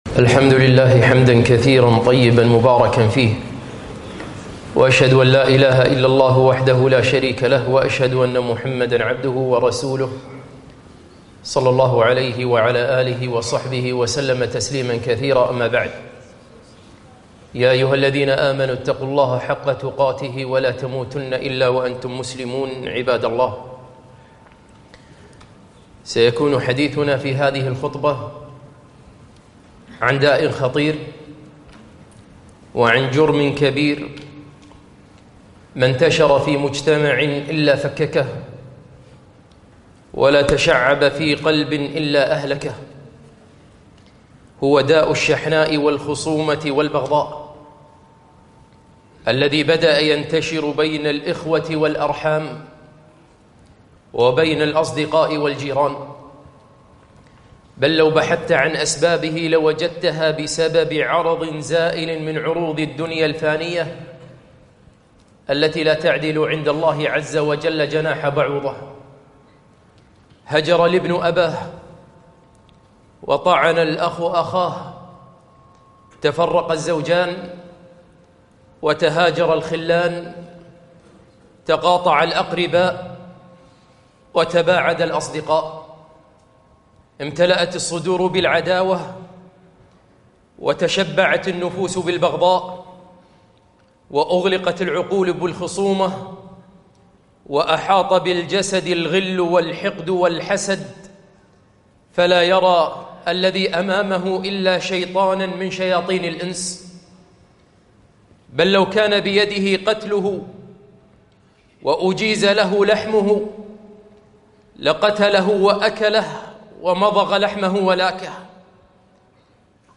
خطبة - داء الأمم الشحناء والبغضاء والخصومة